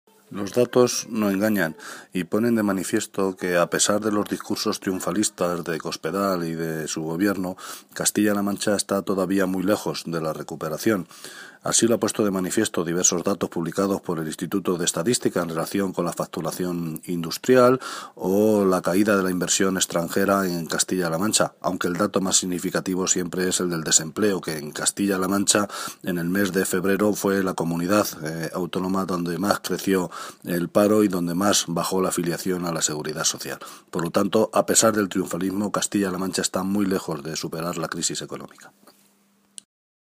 José Luis Martínez Guijarro
Cortes de audio de la rueda de prensa